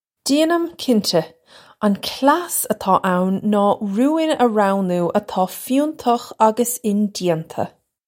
Pronunciation for how to say
Jaynim, kincha. Un klass atá own naw roo-in uh row-noo atá fyoon-tukh uggus in-jayntuh.
This is an approximate phonetic pronunciation of the phrase.